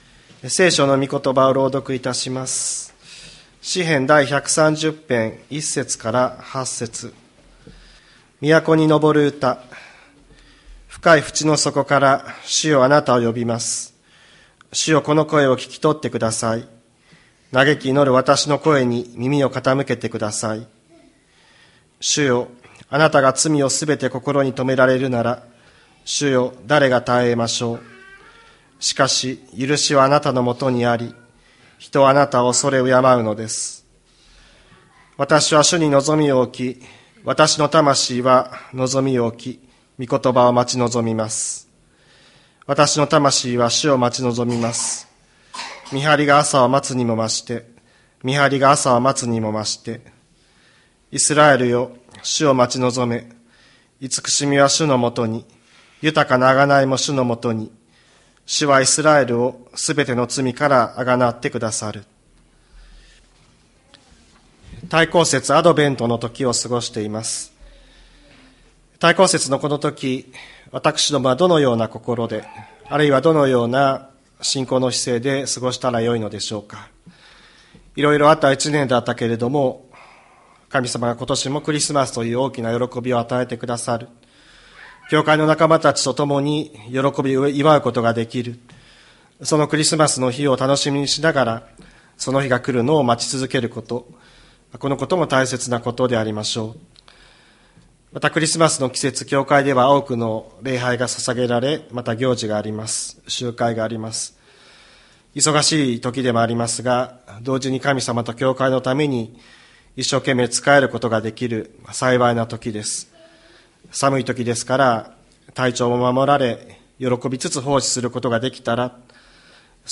千里山教会 2024年12月08日の礼拝メッセージ。